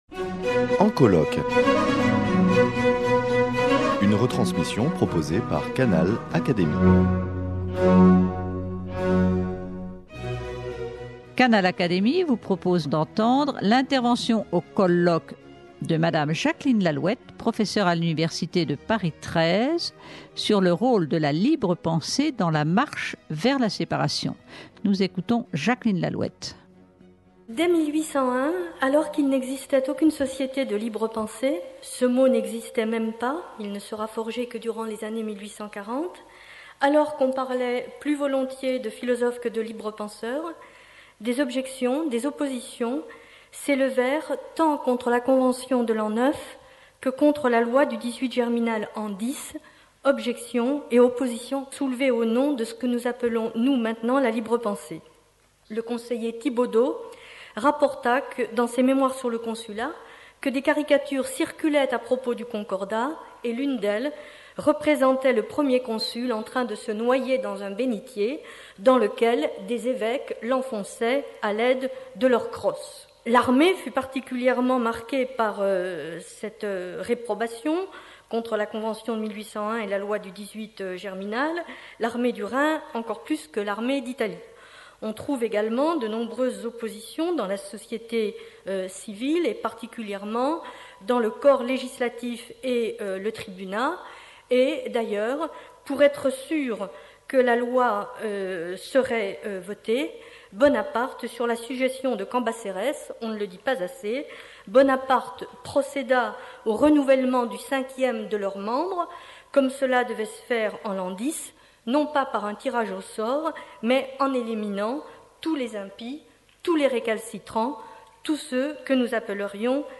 Communication prononcée lors du colloque organisé par l'Académie des Sciences morales et politiques, pour commémorer la loi 1905 de séparation des Eglises et de l'Etat.